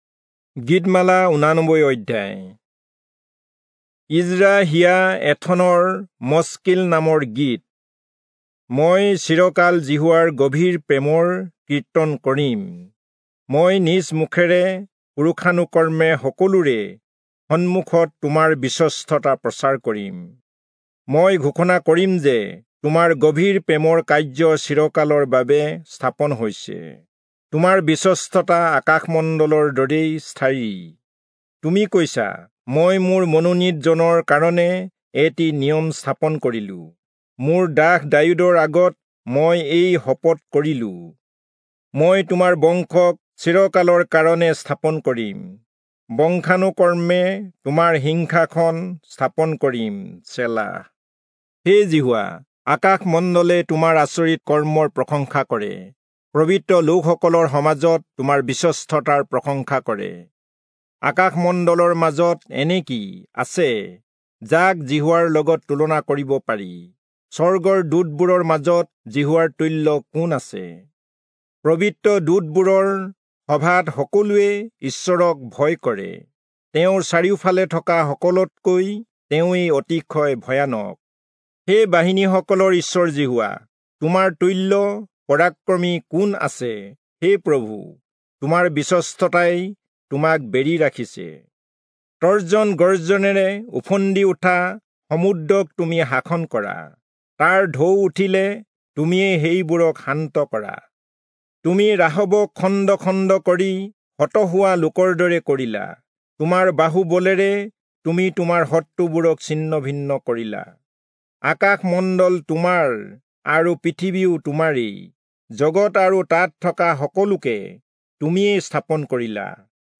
Assamese Audio Bible - Psalms 44 in Ervta bible version